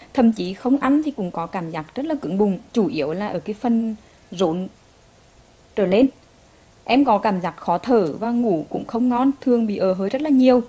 Text-to-Speech
female